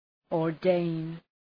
Προφορά
{ɔ:r’deın}